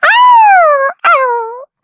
Taily-Vox_Happy3_kr.wav